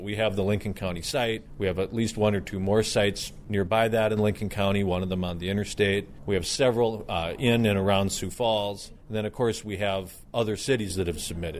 A new consultant’s report shows the problem is actually about twice the size officials thought…because the prison population is also growing. But task force chairman, Lieutenant Governor Tony Venhuizen says for now the task at hand is deciding the location for a new men’s facility.